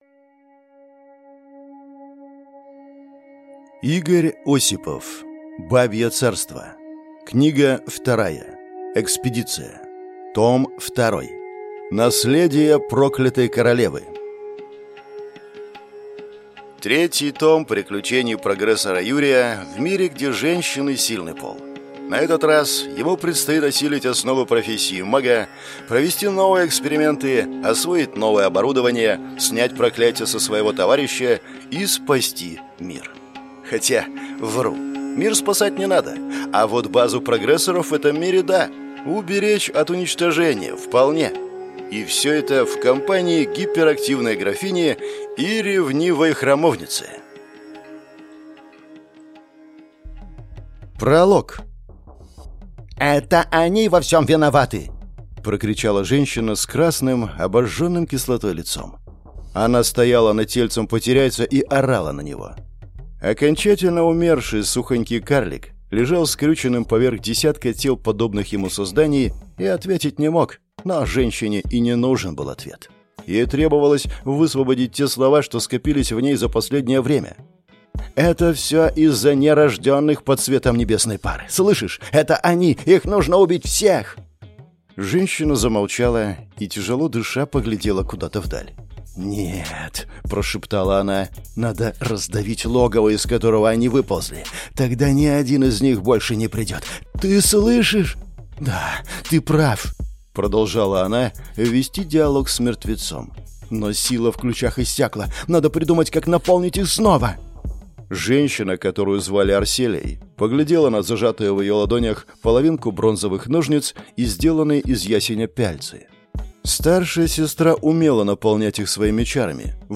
Аудиокнига Экспедиция. Том 2. Наследие проклятой королевы | Библиотека аудиокниг